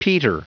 Prononciation du mot peter en anglais (fichier audio)
Prononciation du mot : peter